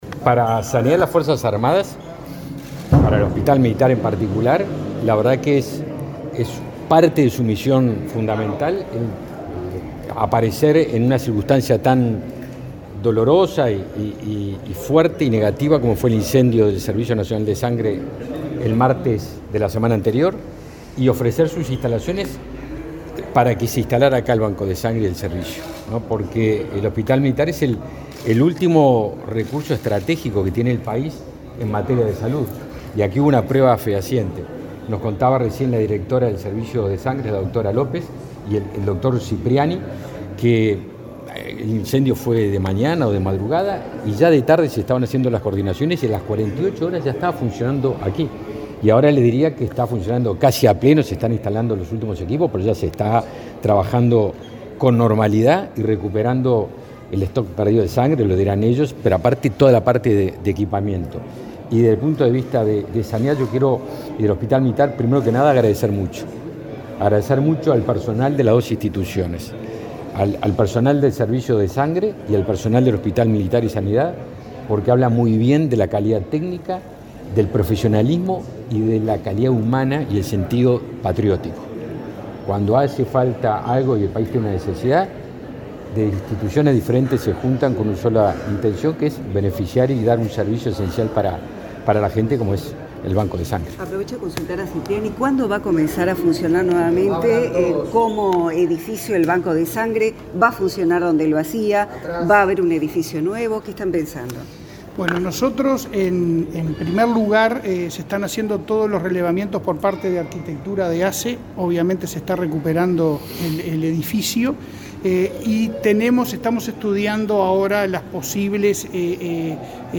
Declaraciones del ministro de Defensa y el presidente de ASSE